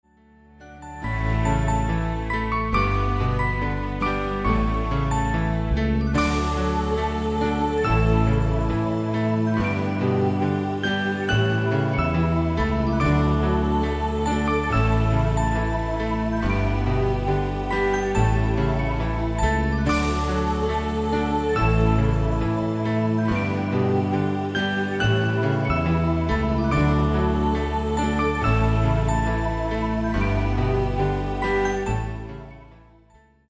Jest prosta, melodyjna i z przyjemnością odbierana.